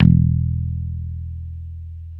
Index of /90_sSampleCDs/Roland L-CD701/BS _Rock Bass/BS _Dan-O Bass